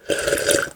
stamina_sip.2.ogg